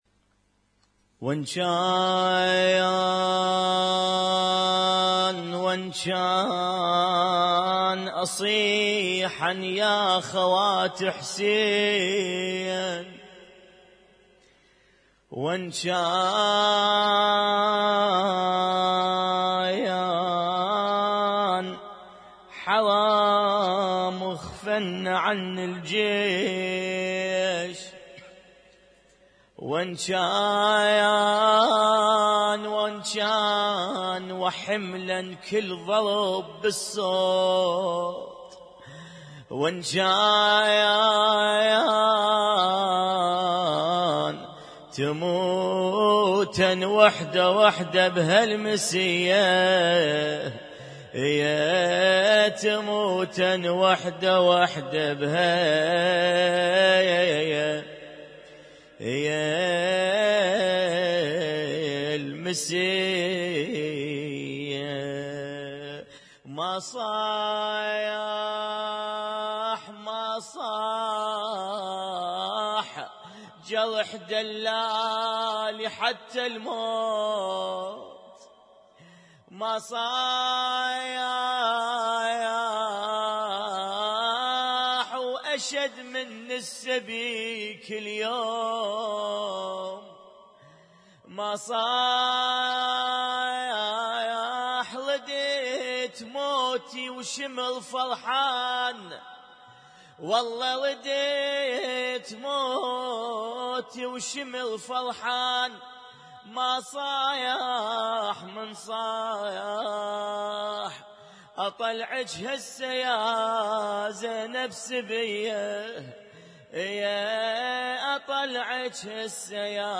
Husainyt Alnoor Rumaithiya Kuwait
القارئ: الرادود